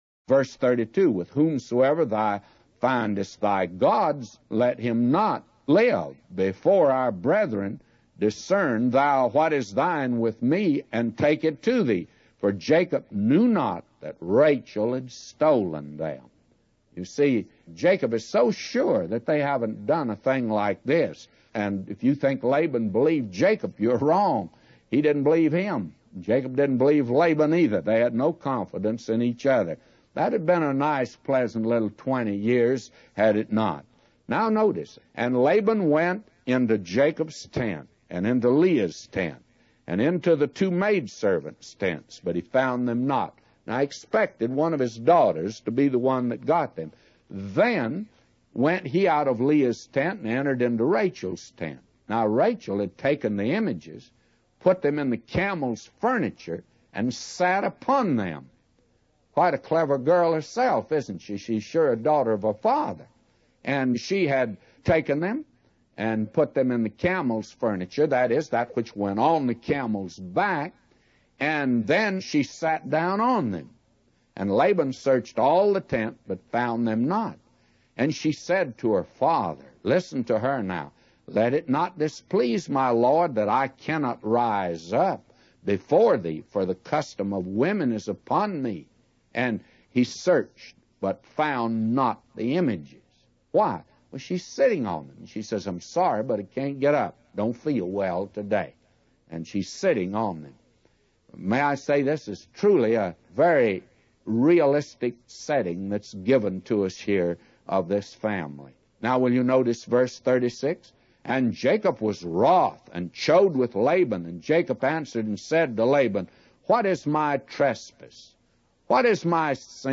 A Commentary By J Vernon MCgee For Genesis 31:32-999